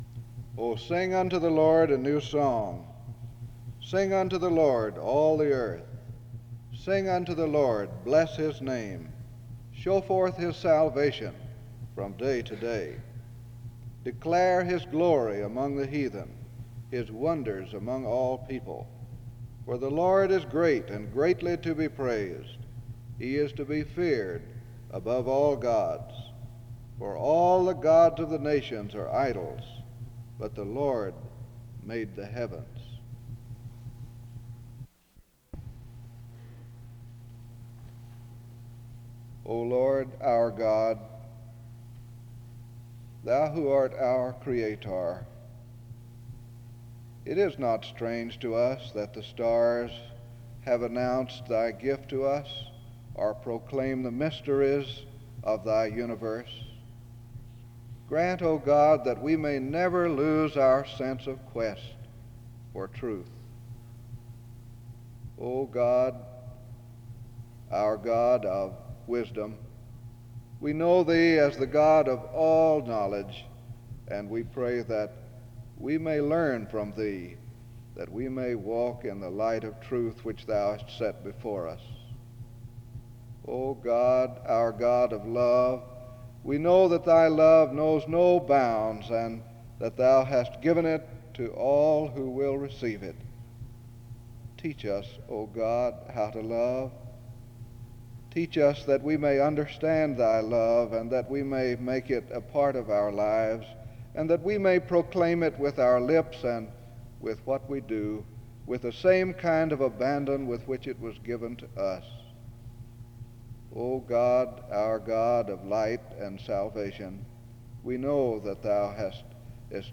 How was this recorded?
SEBTS Chapel and Special Event Recordings - 1960s